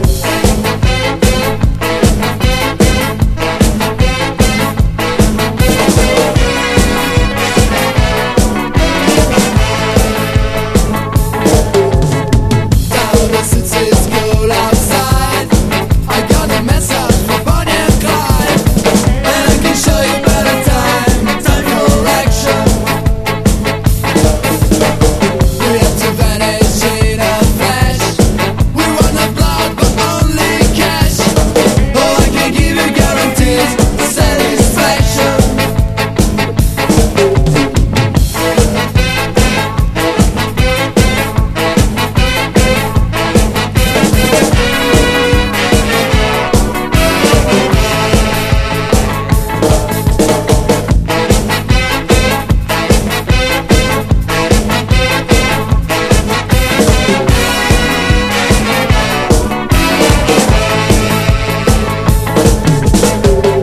ROCK / PUNK / 80'S～ / POP PUNK / SKA PUNK
大人気！ヒット曲パンク・カヴァー・アルバム！